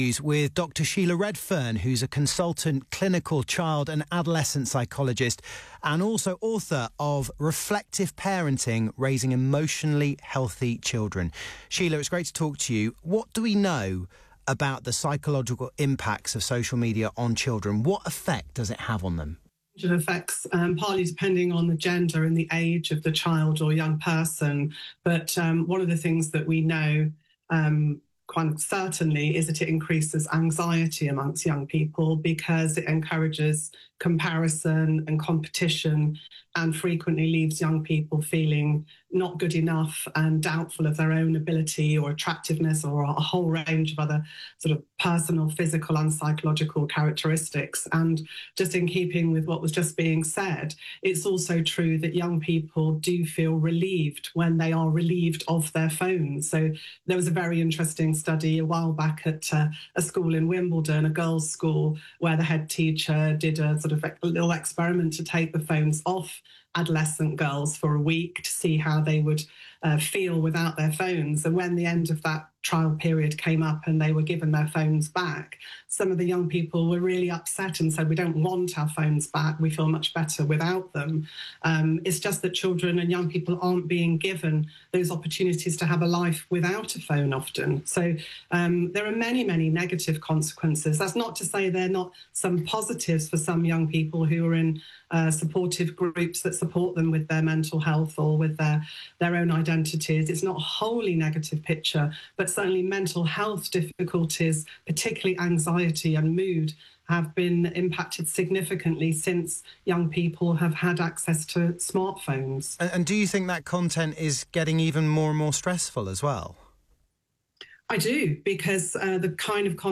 talks live on LBC news about the impact of social media on young people